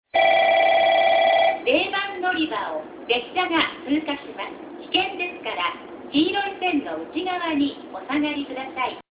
0番のりば通過放送　女声
▽は0番のりばの旧放送、旧携帯電話機材での収録です。